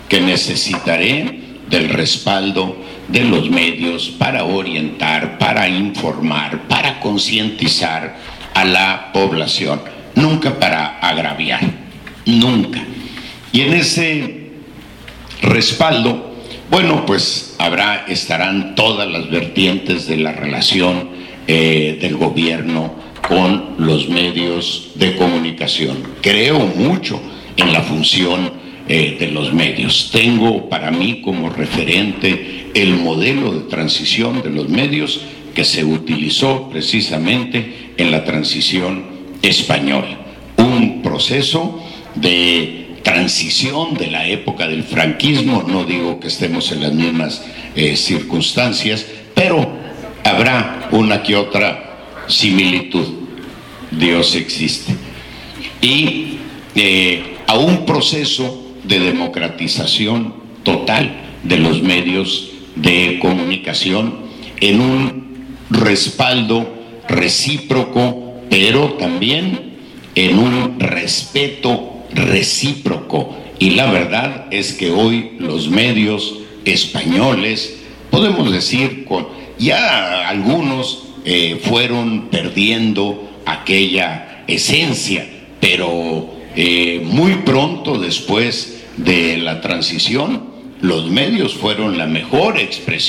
HERMOSILLO, SONORA,MX.──En lo que fue su última actividad proselitista aquí en la capital del estado, el candidato a la gubernatura  por la alianza Morena PVEM,PANAL,Alfonso Durazo Montaño,dijo que como Gobernador su relación con los medios será abierta y transparente habrá un respaldo a todas las vertientes pero ese respaldo deberá ser recíproco